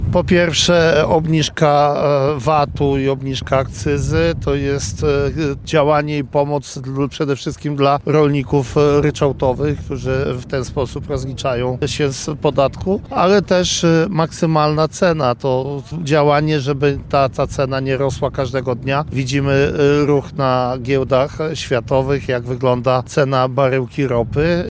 Stefan Krajewski podkreślił w rozmowie z Radiem Nadzieja, że rządowy pakiet ustaw obniżający podatek VAT oraz akcyzę pomógł tym rolnikom, którzy rozliczają się na ryczałcie.